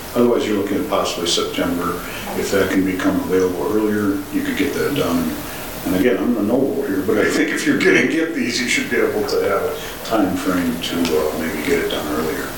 Although Councilor Pat May said he would vote no, he asked if the ordinance could be amended to grant permits immediately after the not yet produced educational video is ready for viewing, instead of three months from adoption, in order to allow residents to get permits quicker: